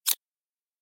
menu-direct-hover.ogg